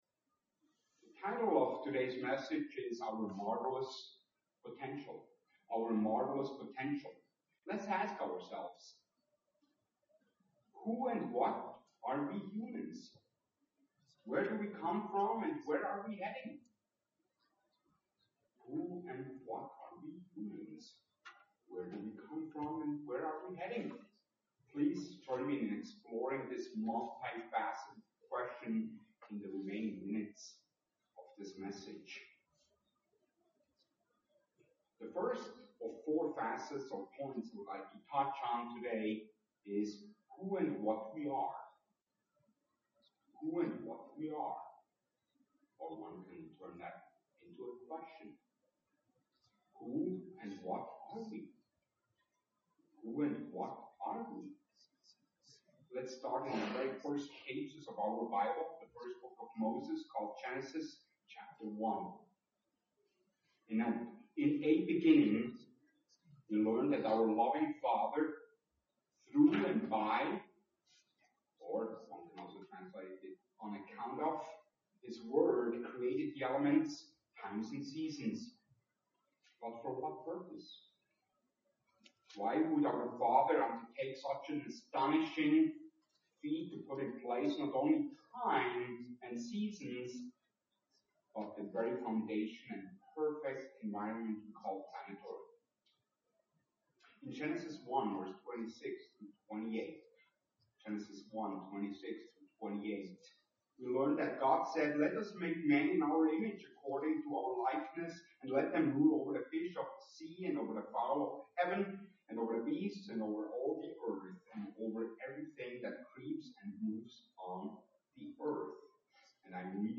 Given in Twin Cities, MN
UCG Sermon potential Studying the bible?